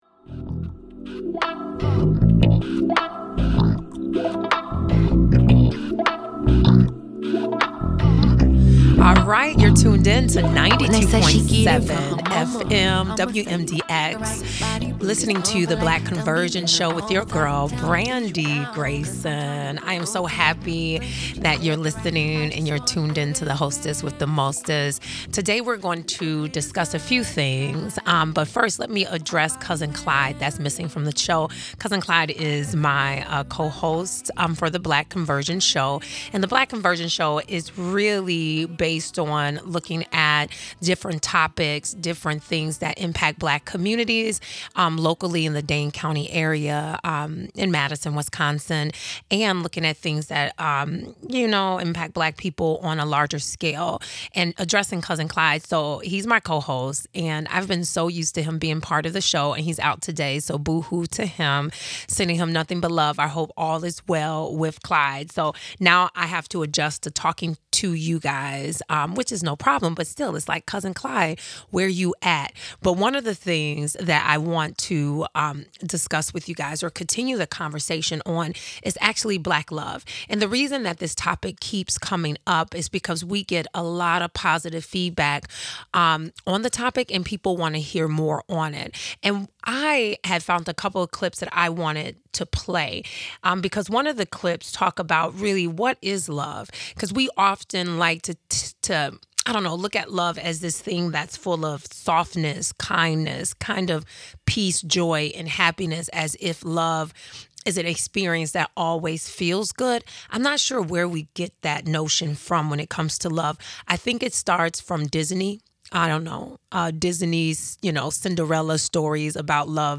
Discussions, guests, and interviews will focus on vulnerable populations--specifically Black families.